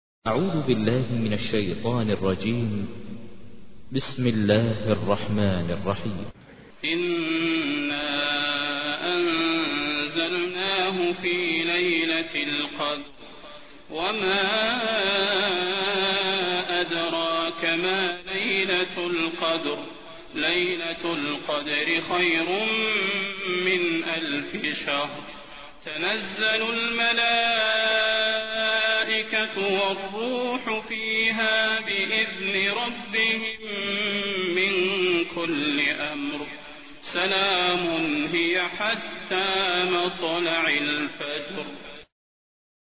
تحميل : 97. سورة القدر / القارئ ماهر المعيقلي / القرآن الكريم / موقع يا حسين